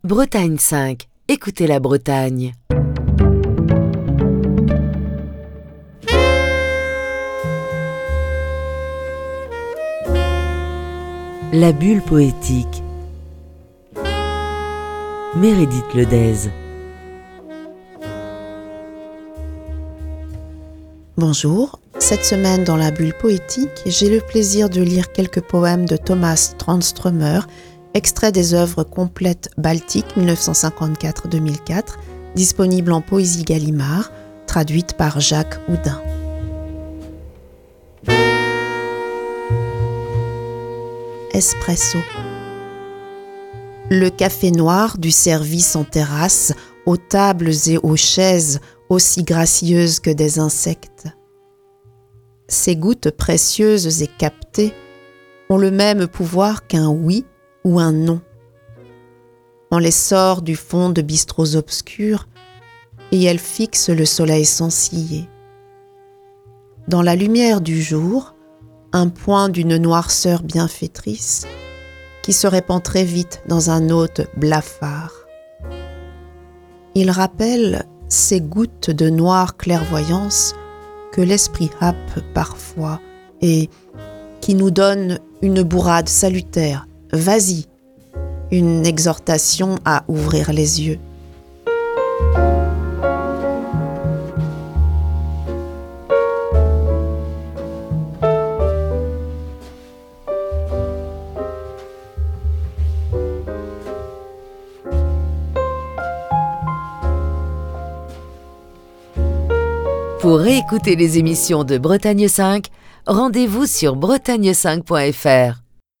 lit des textes du poète suédois Tomas Tranströmer